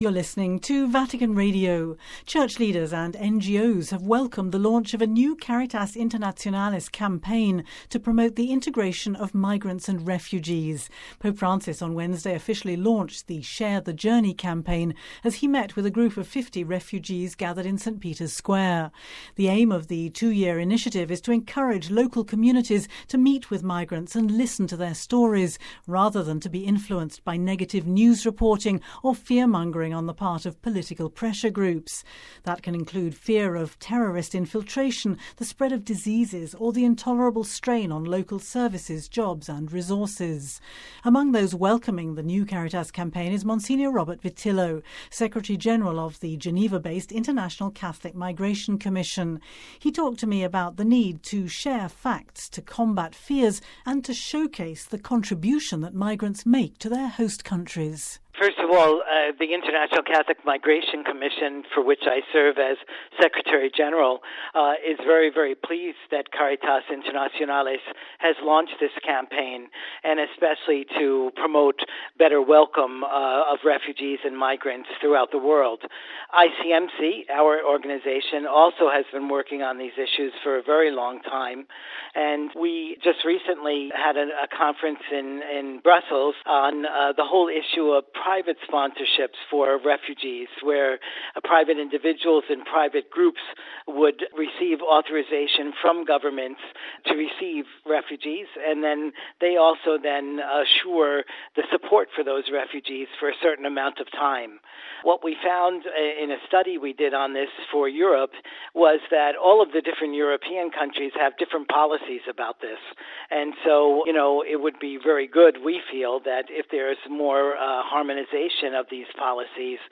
(Vatican Radio) Church leaders and NGOs have welcomed the launch of a new Caritas Internationalis campaign to promote the integration of migrants and refugees.